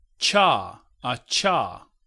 ملف:Voiceless palato-alveolar affricate.ogg - المعرفة
English: A pronunciation of a Voiceless postalveolar affricate|voiceless postalveolar affricate|Voiceless postalveolar affricate [t͡ʃ] in a context of an open front unrounded vowel [a] . transcription: [t͡ʃaː aˈt͡ʃaː]
Voiceless_palato-alveolar_affricate.ogg.mp3